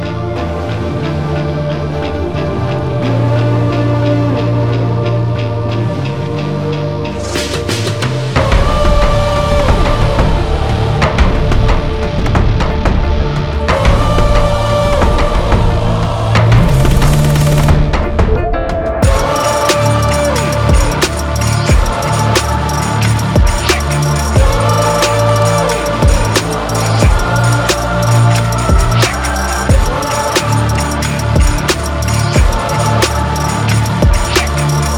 Жанр: Танцевальные / Музыка из фильмов / Саундтреки